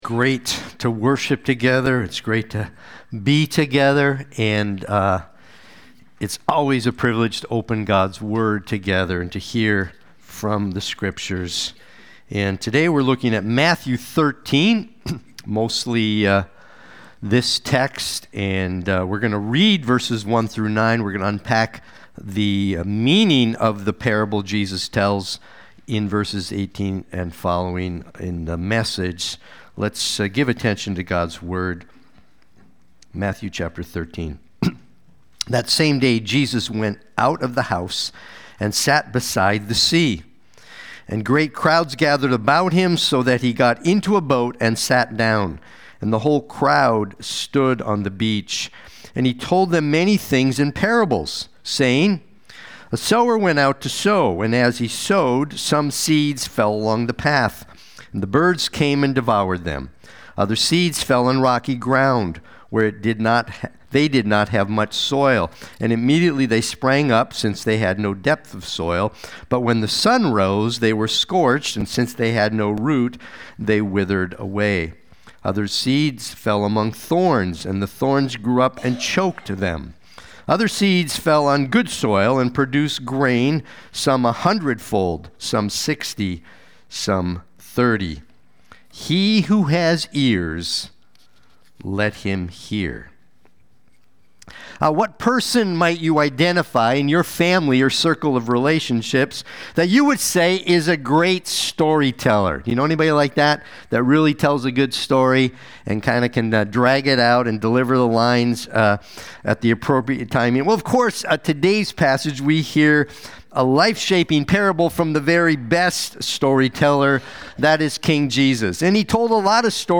Watch the replay or listen to the sermon.
Sunday-Worship-main-11826.mp3